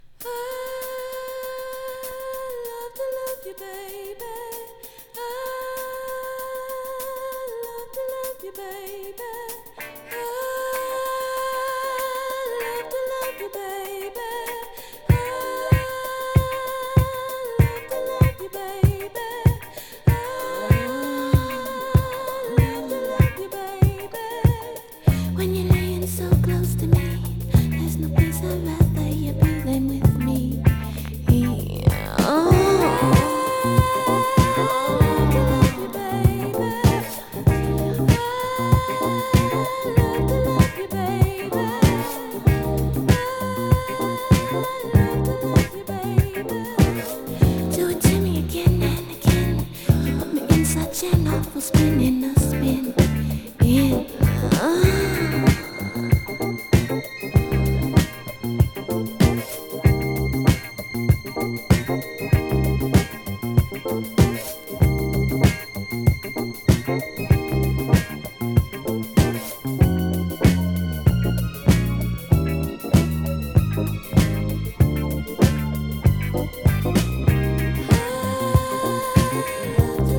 ゆったりとしたリズムに艶かしい唄声が溶けるエロティック・ディスコ！